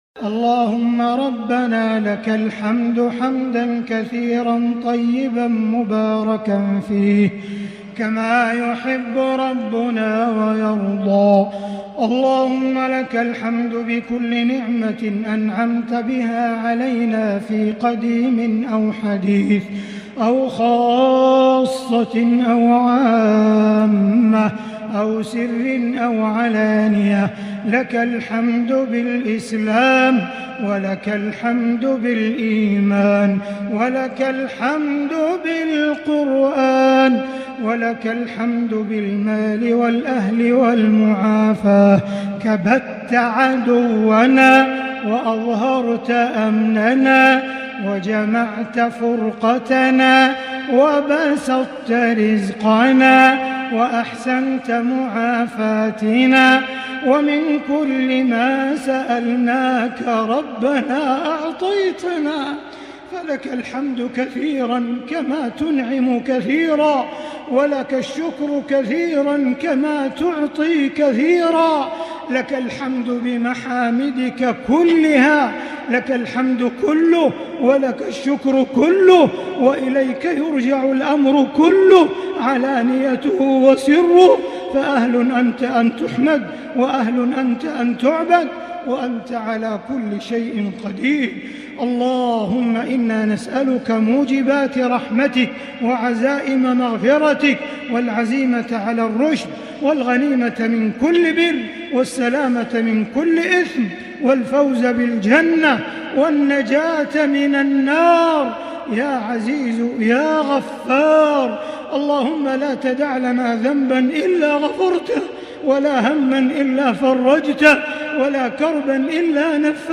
دعاء القنوت ليلة 23 رمضان 1442هـ | Dua for the night of 23 Ramadan 1442H > تراويح الحرم المكي عام 1442 🕋 > التراويح - تلاوات الحرمين